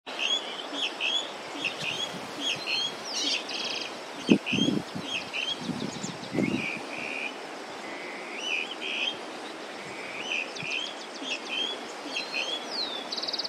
Plumbeous Rail (Pardirallus sanguinolentus)
Life Stage: Adult
Detailed location: Reserva Natural Urbana de General Pico (laguna La Arocena)
Condition: Wild
Certainty: Recorded vocal